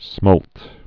(smōlt)